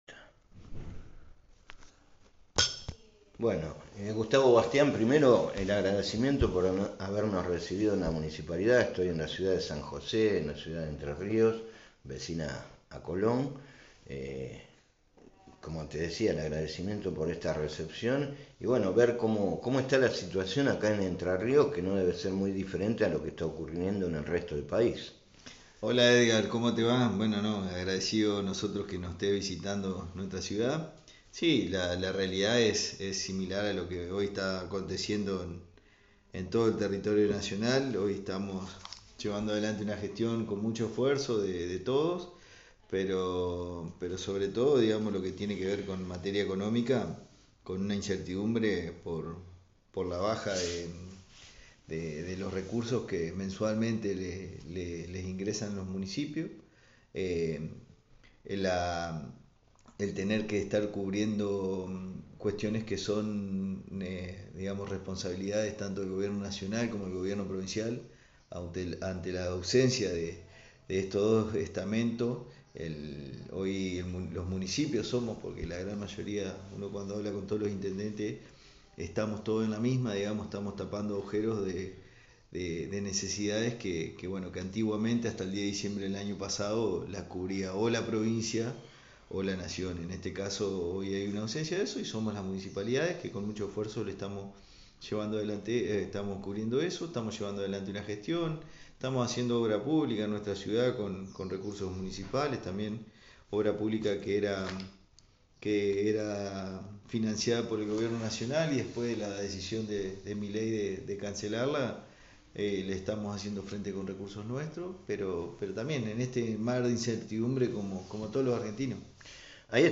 El equipo de A1 Noticias fue recibido en San José por Gustavo Bastian, presidente comunal y vicepresidente de la Liga de Intendentes Justicialistas de Entre Ríos. Repasamos una variada agenda que incluyó la situación social, la obra pública, la posible situación del pago de luminaria pública y la falta de apoyo a instituciones